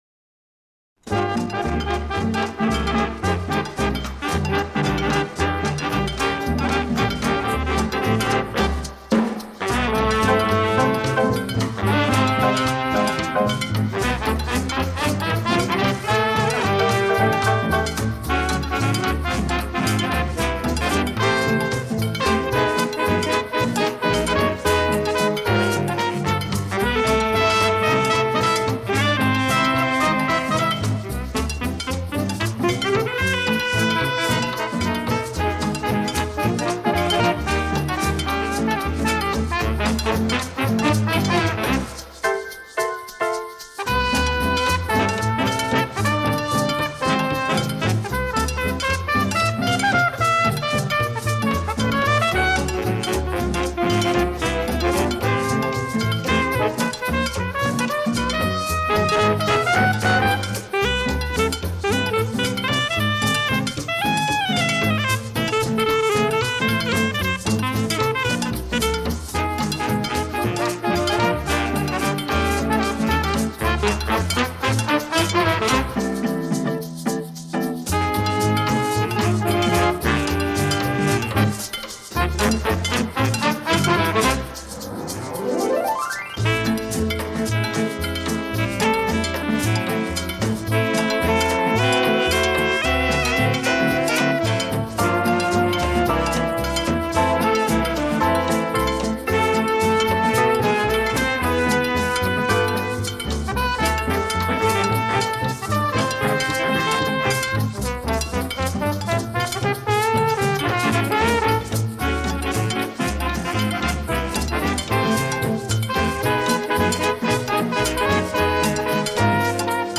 Новые поступления СТАРОГО РАДИО. Инструментальная музыка советских композиторов (ч. 10-я).